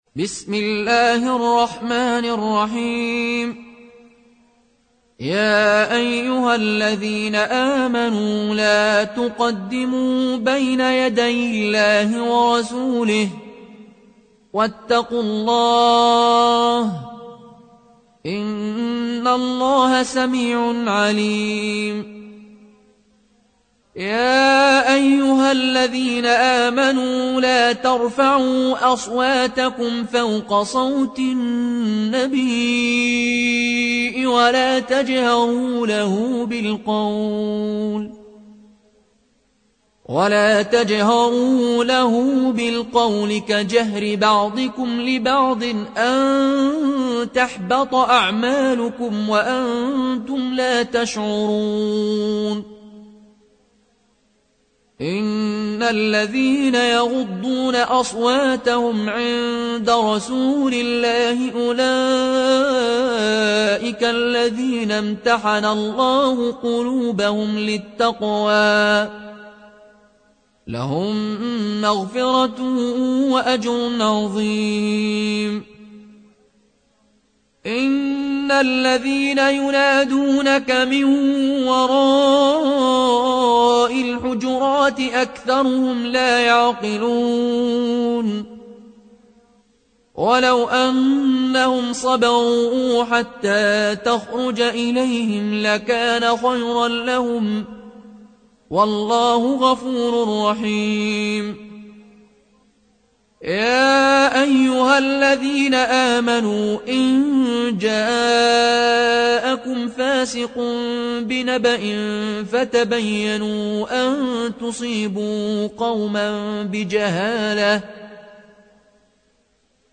(رواية قالون)